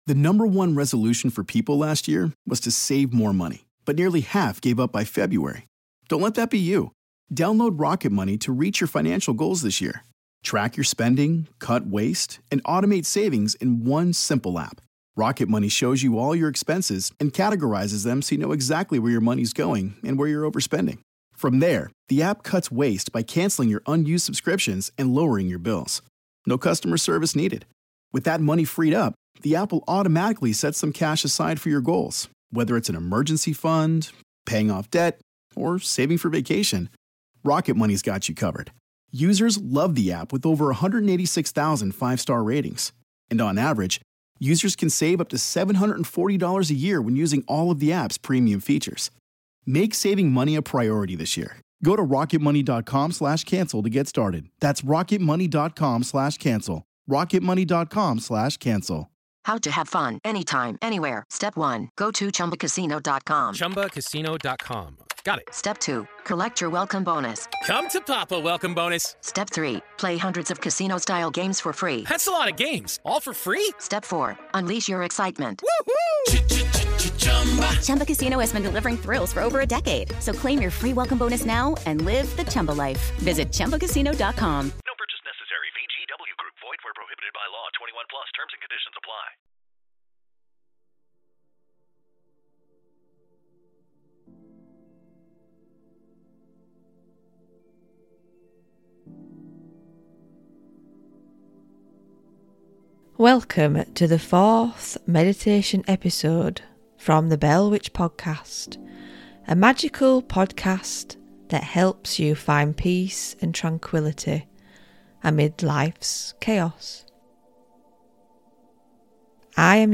Water Meditation